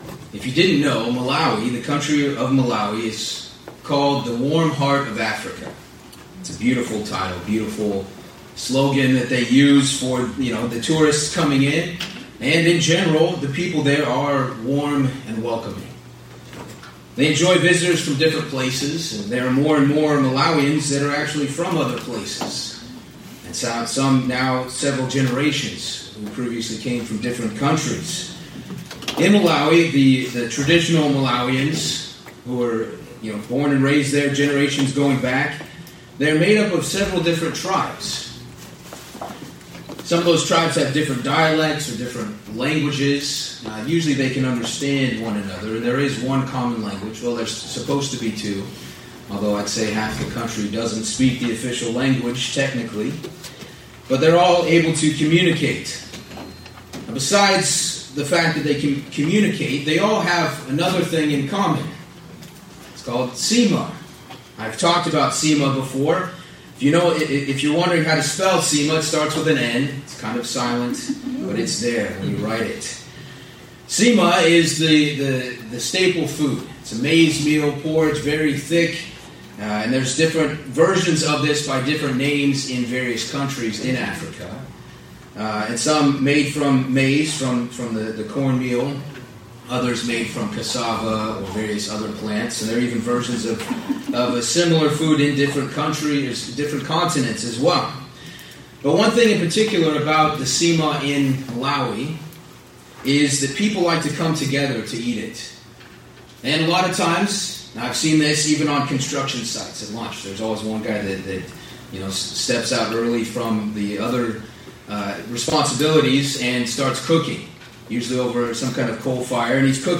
Sermons
Given in New York City, NY New Jersey - North